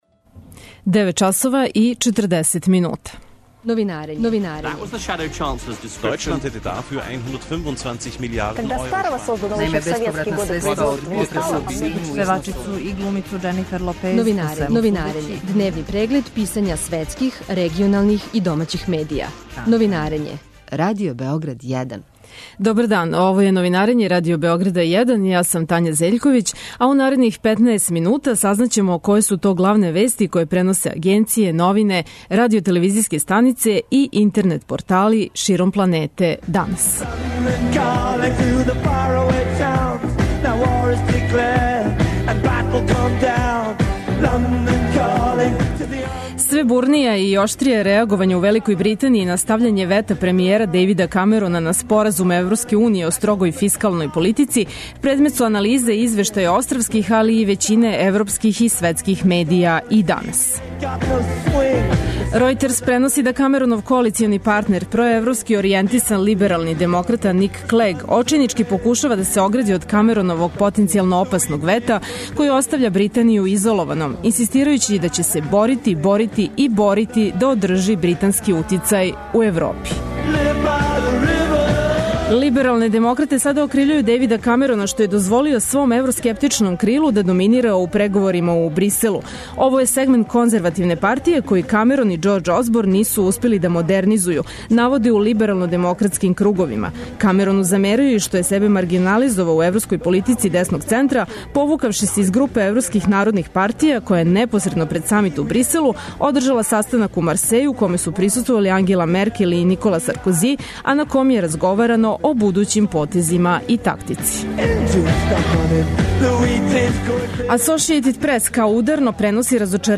Преглед штампе у трајању од 15 минута. Чујте које приче су ударне овога јутра за највеће светске станице и листове, шта се догађа у региону и шта пише домаћа штампа.